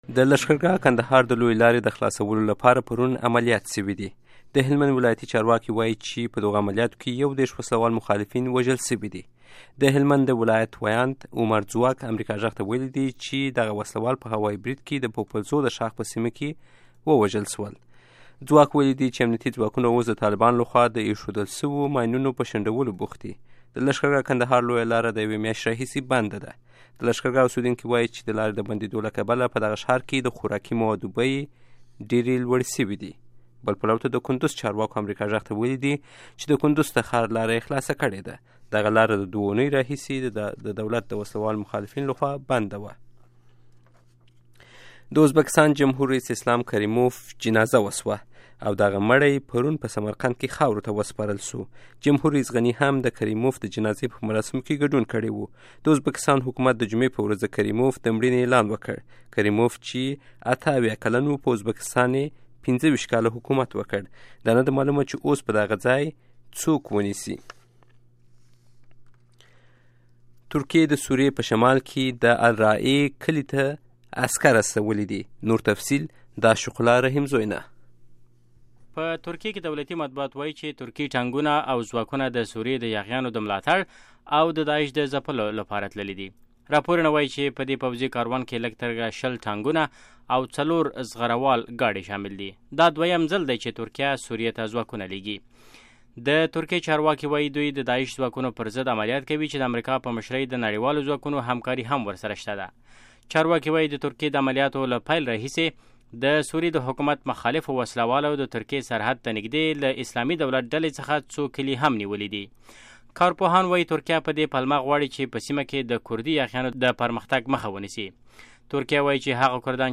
د افغانستان او نړۍ تازه خبرونه په ۳ دقیقو کې